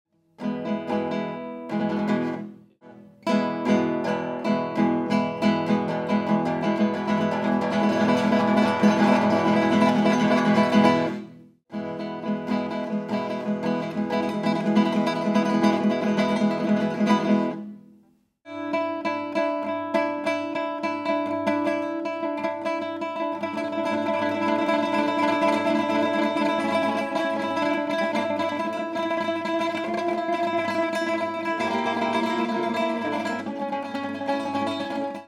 69. Rasgueos.m4v